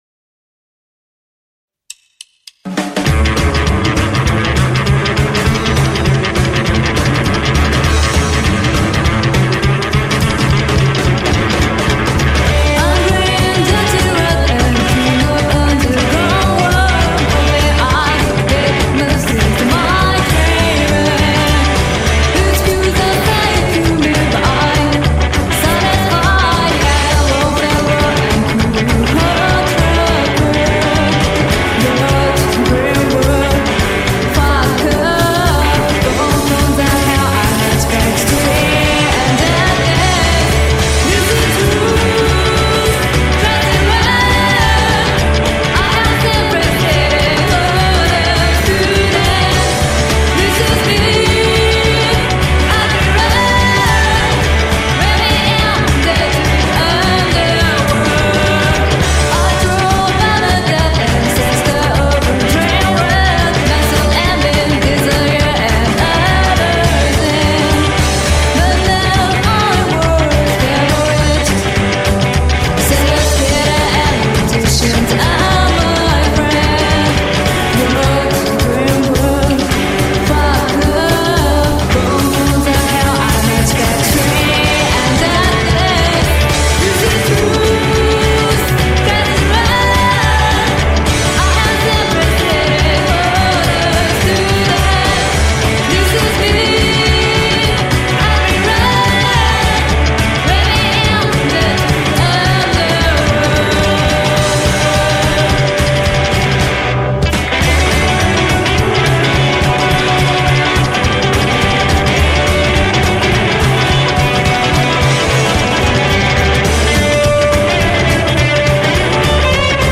Neo rockabilly / psychobilly band from Japan.